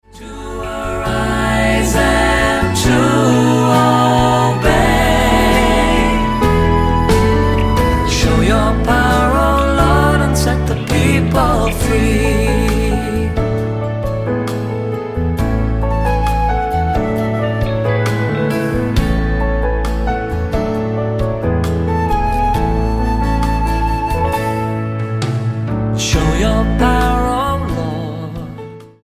STYLE: MOR / Soft Pop
Pleasant praise and worship.